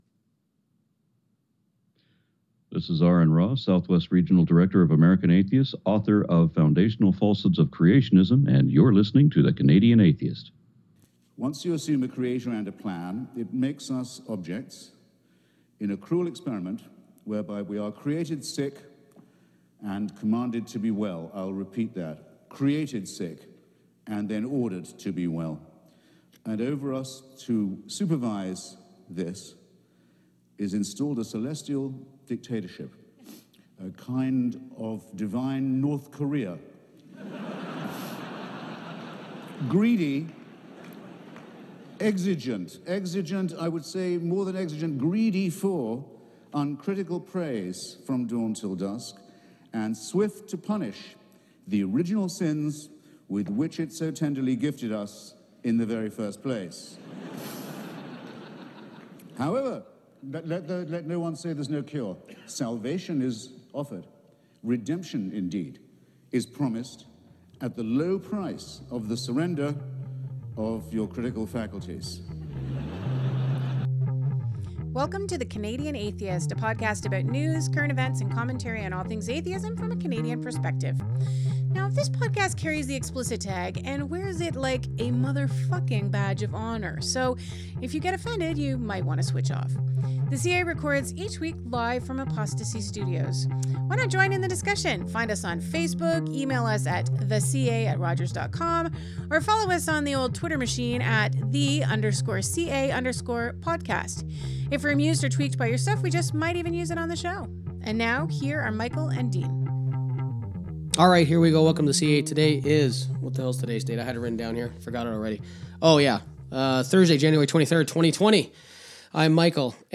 This is a great conversation & much fun was had. It’s amazing when two people just talk!